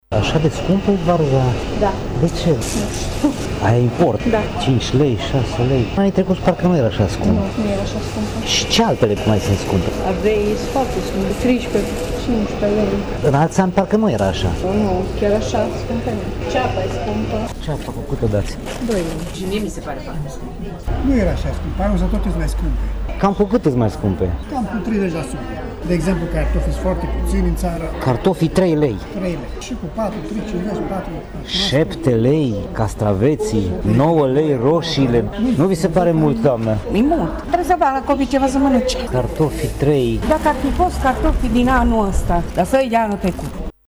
Producătorii și vânzătorii de la tarabe spun că într-adevăr produsele sunt mai scumpe în acest an, chiar și cu o treime, dar sunt nevoiți să le vândă la acest preț pentru a-și acoperi cheltuielile: